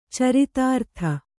♪ caritārtha